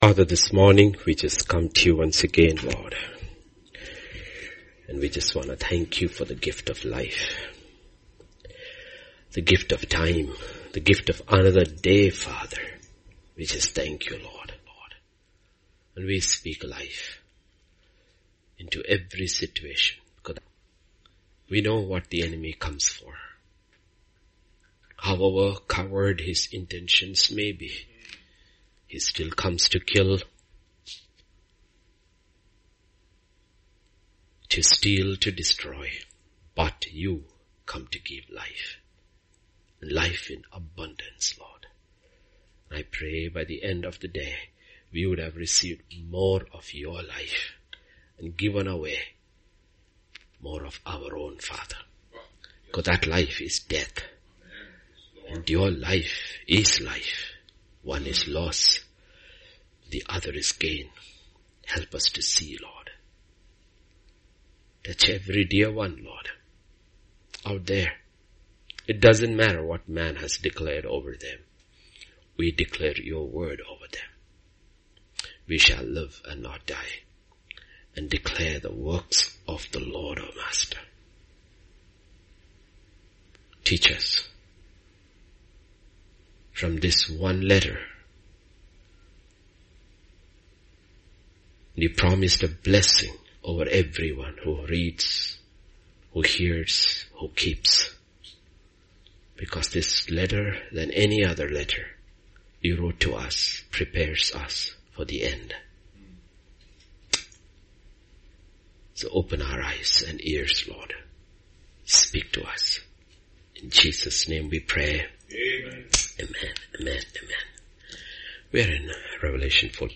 Grace Tabernacle Church Hyderabad - Sermons podcast To give you the best possible experience, this site uses cookies.